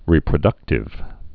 (rēprə-dŭktĭv)